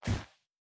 Land Step Snow B.wav